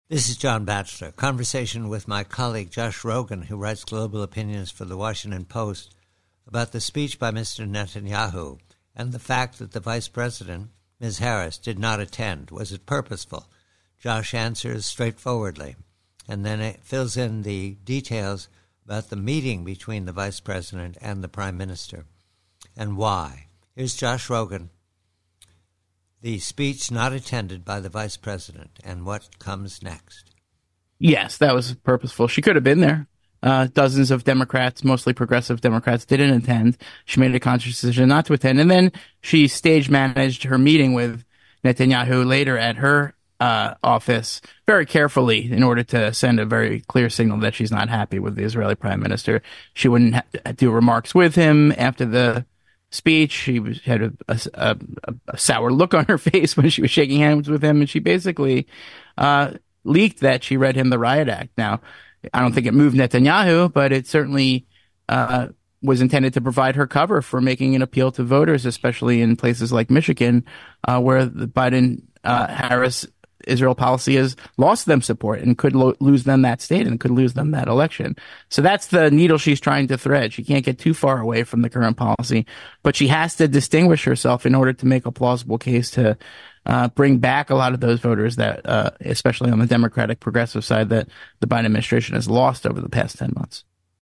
PREVIEW: VPOTUS: HARRIS: Conversation with colleeague Josh Rogin of Washington Post re the Harris foreign policy in Ukraine, Middle East, China.